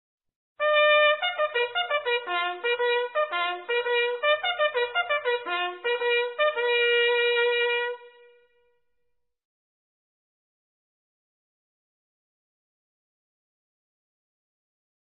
2nd dinner call
2nd-dinner-alarm.mp3